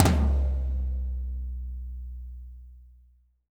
-TOM 2N   -L.wav